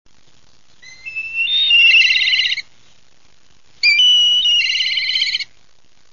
Kania czarna - Milvus migrans
głosy